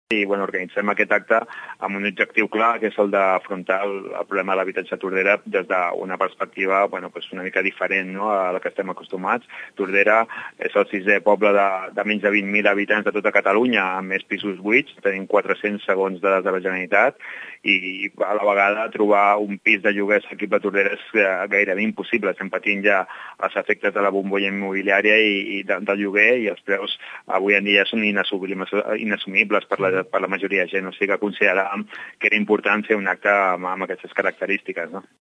Amb l’objectiu d’afrontar la problemàtica dels habitatges buits, es vol presentar aquest fet des d’una perspectiva local. Ho explica el regidor de Som Tordera, Salvador Giralt.